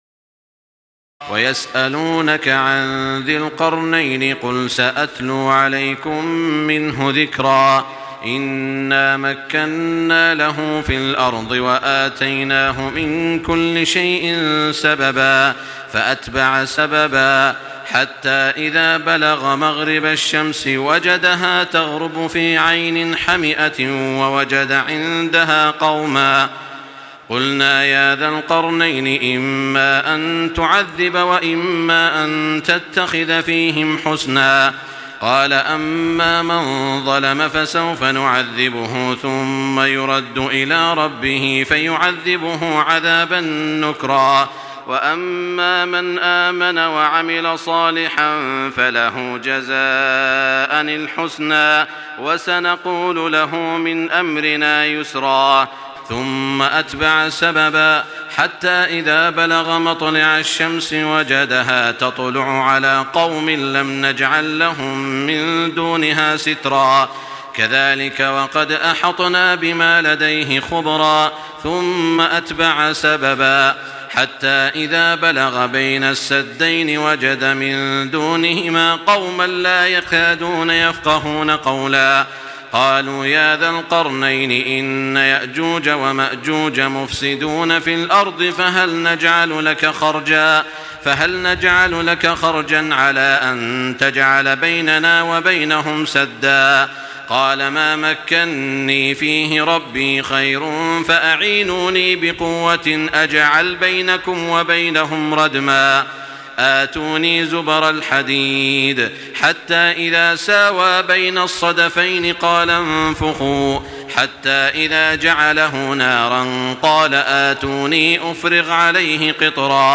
تراويح الليلة الخامسة عشر رمضان 1425هـ من سورتي الكهف (83-110) و مريم كاملة Taraweeh 15 st night Ramadan 1425H from Surah Al-Kahf and Maryam > تراويح الحرم المكي عام 1425 🕋 > التراويح - تلاوات الحرمين